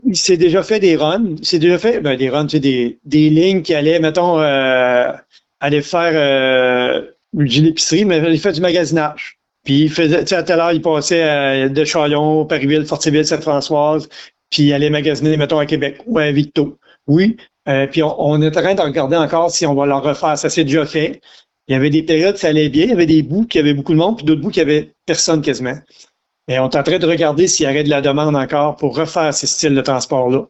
En entrevue, le préfet de la MRC, Mario Lyonnais, a indiqué qu’ils regardaient cette possibilité.